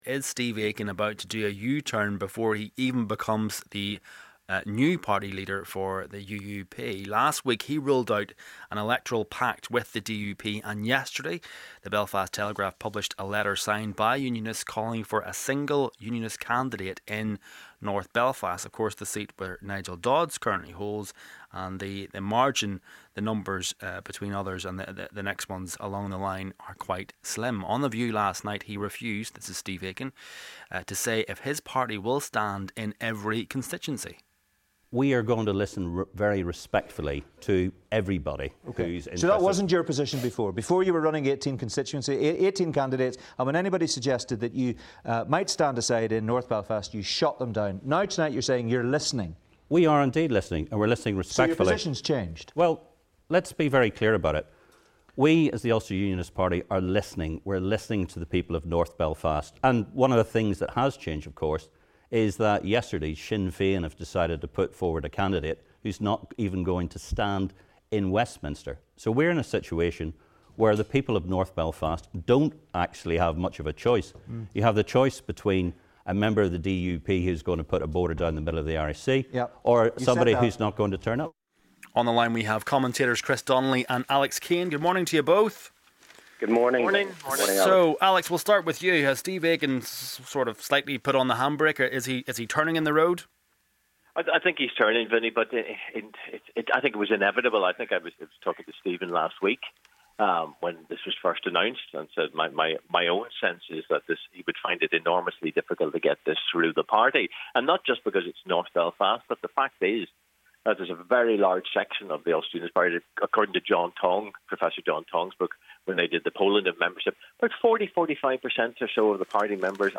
got reaction from political commentators